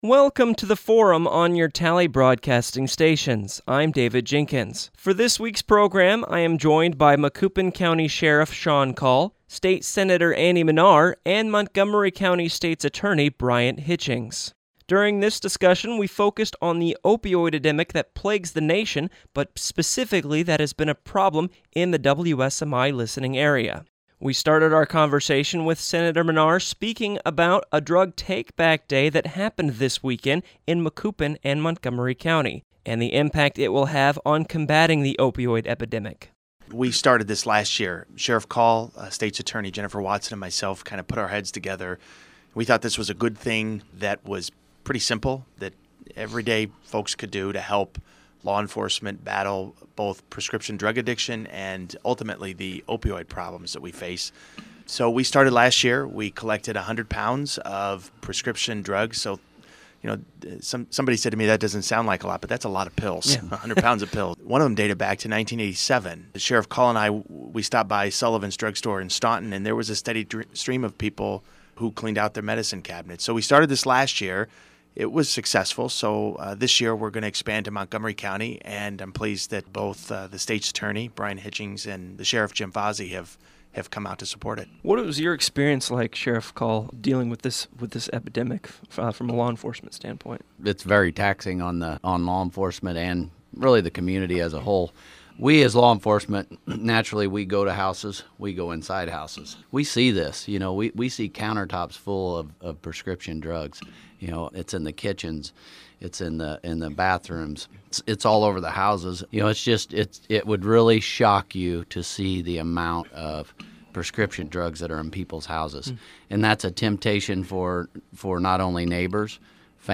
Guests: Macoupin County Sheriff Shawn Kahl, State Senator Andy Manar, Montgomery County State's Attorney Bryant Hitchings Topic: America's Opioid Epidemic and how citizens can help combat it.